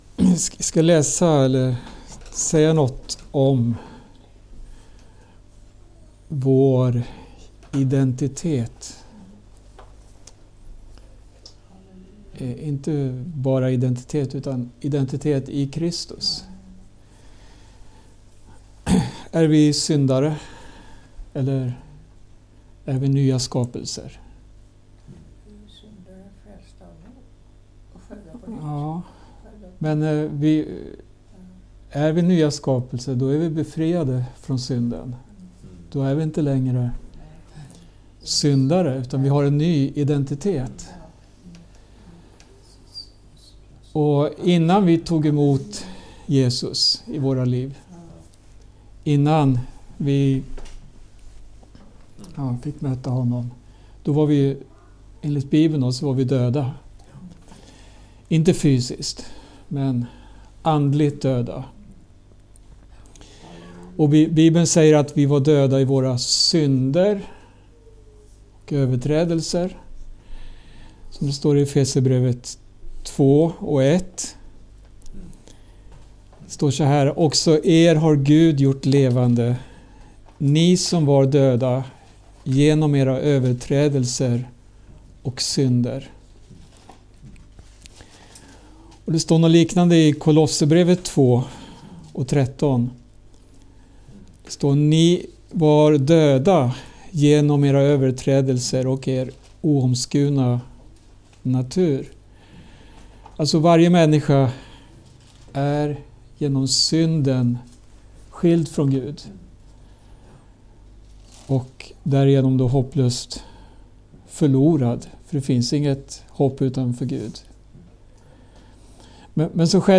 Undervisning
inspelat hos församlingen i Skälby den 7 maj 2025.